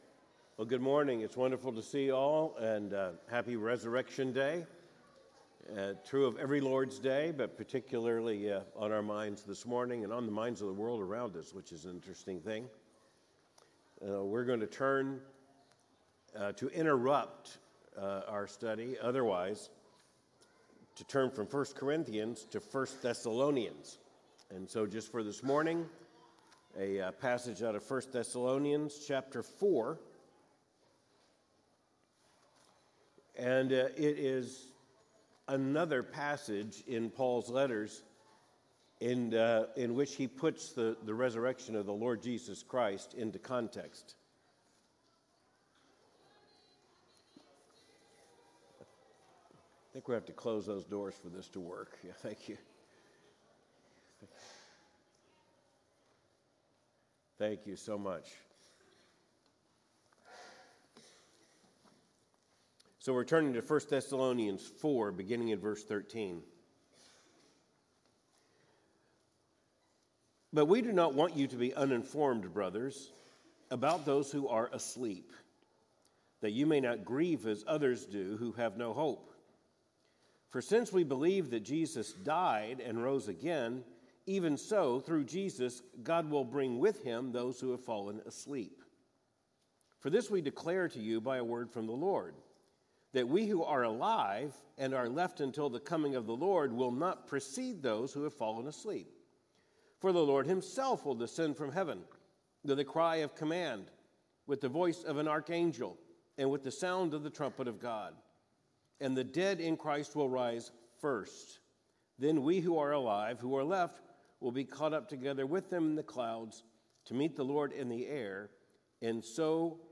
Cultural commentary from a Biblical perspective Third Avenue Baptist Church Louisville, KY 1 Thessalonians 4:13–18 April 20, 2025